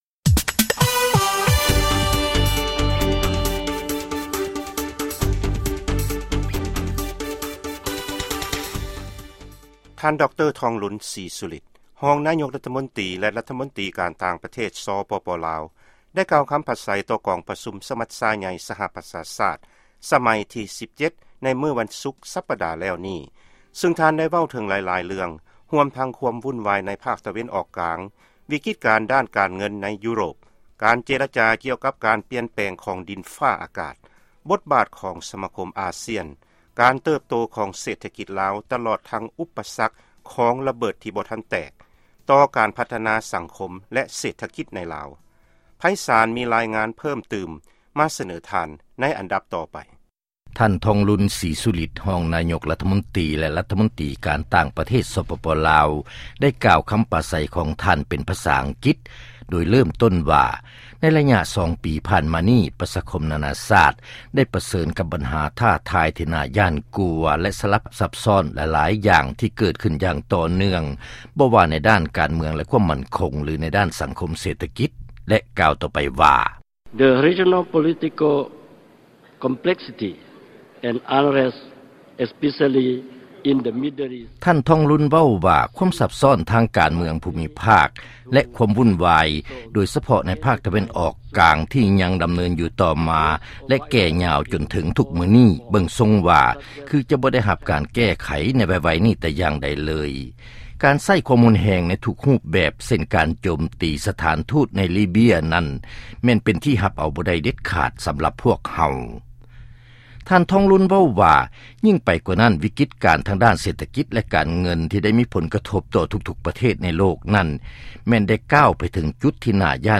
ຟັງລາຍງານ ຄຳປາໄສ ຂອງທ່ານທອງລຸນ